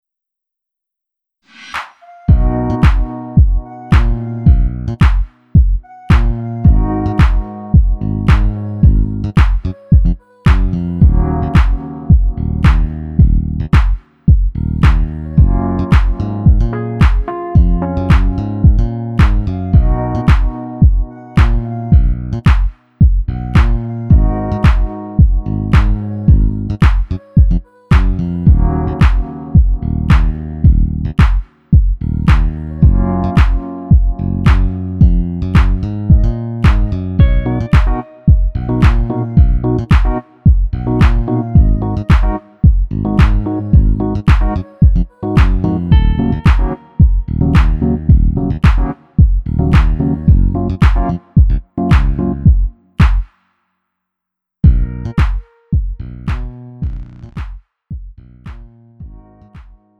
장르 구분 Lite MR